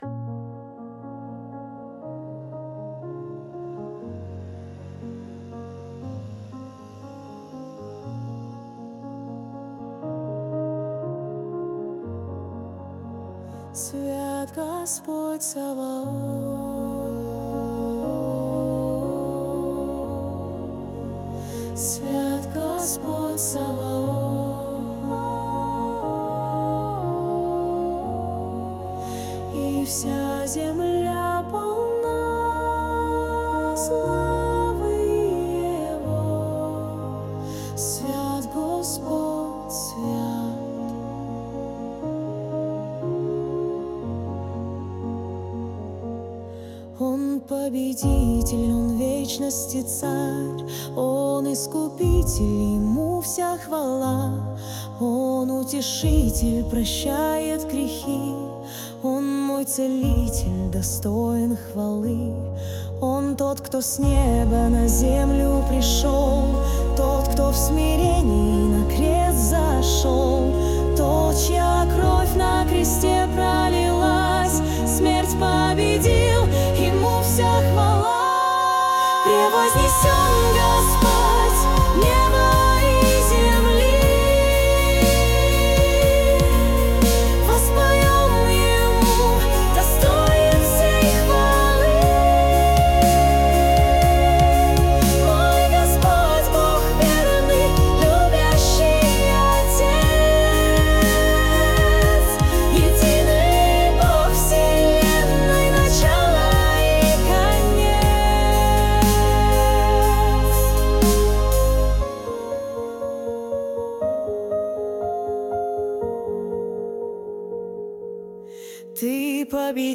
песня ai